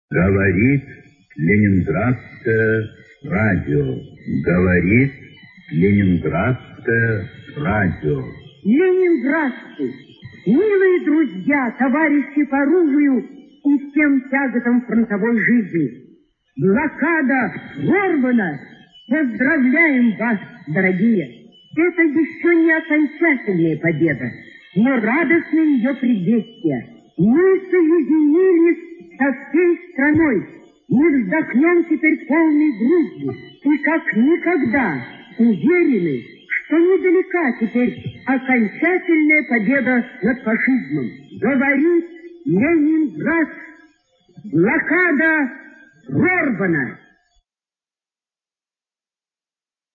приводной маяк из 43 года